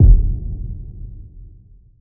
FlashbandExplode.wav